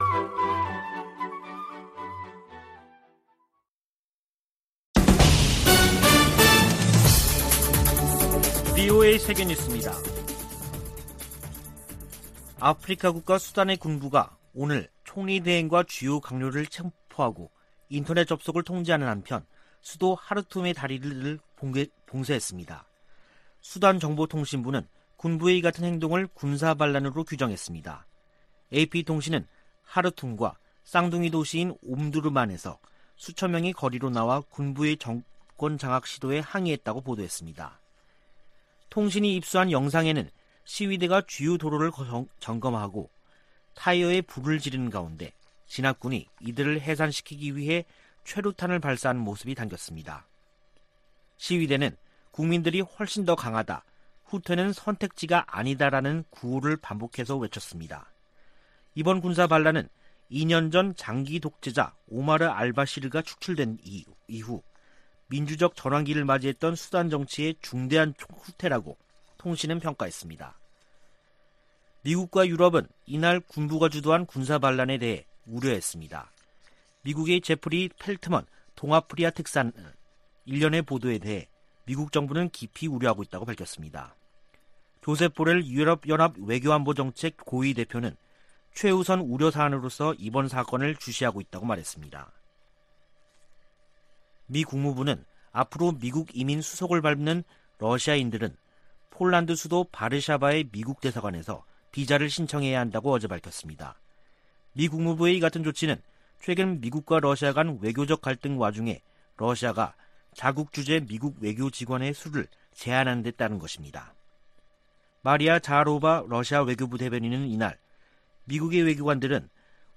VOA 한국어 간판 뉴스 프로그램 '뉴스 투데이', 2021년 10월 25일 2부 방송입니다. 성 김 미 대북특별대표는 한반도 종전선언 등 관여 방안을 계속 모색하겠다면서도 북한의 탄도미사일 발사를 도발이라고 비판했습니다. 제76차 유엔총회에 북한 핵과 탄도미사일 관련 내용이 포함된 결의안 3건이 발의됐습니다. 북한이 플루토늄 추출과 우라늄 농축 등 핵 활동을 활발히 벌이고 있다는 우려가 이어지고 있습니다.